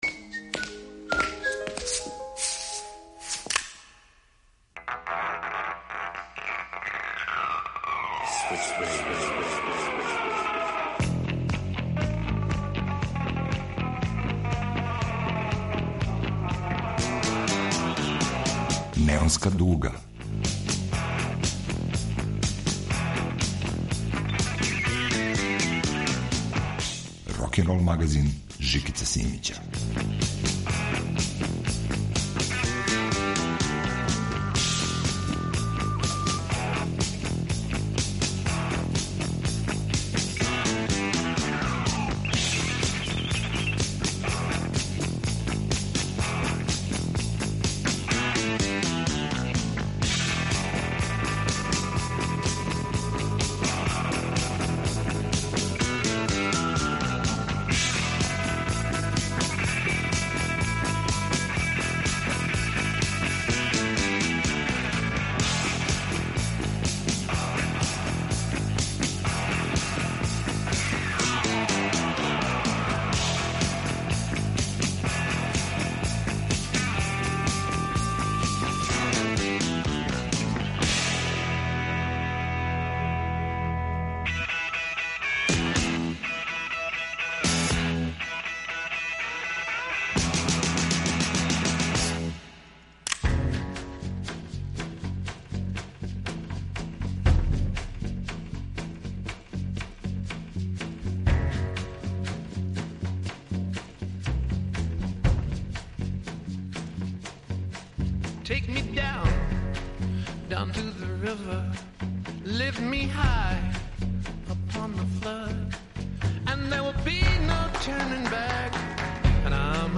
Двадесетак нумера је на репертоару. Старо и ново, традиционално и модерно смењују се у фуриозном ритму рокенрола.